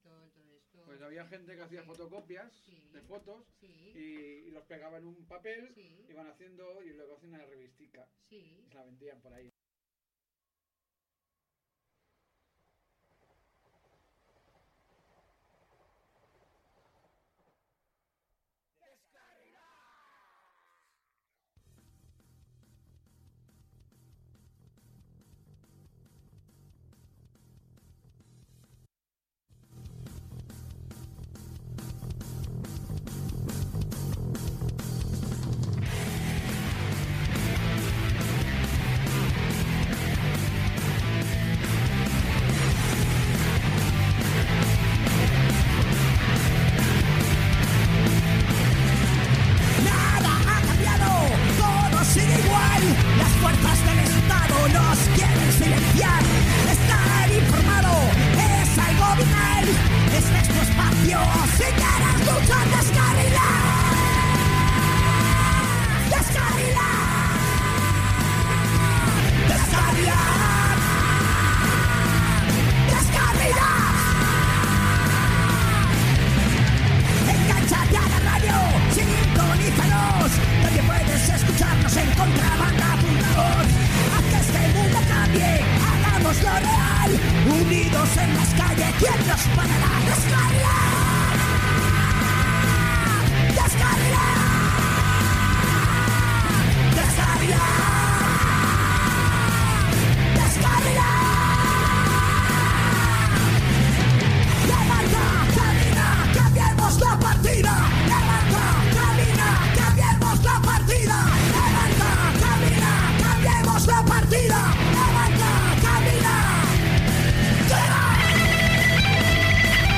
Este miércoles hicimos el programa : “40” 💪🤩 de Deskarrilats en el que hablamos sobre los fanzines y el fenómeno zine en la escena punk, aunque nos remontamos a los inicios de este tipo de publicaciones que tienen varios siglos de existencia. En el programa como ya es habitual superando fallos varios ,seguimos adelante 💪😎 poniendo canciones relacionadas con el tema del programa ,hablando de lo que nos pareció y pasándolo bien .
Tuvimos la colaboración habitual de nuestros compas de programa en la sección de conciertos escuchando un audio de la crónica de los últimos conciertos en que asistieron gente de Deskarrilats.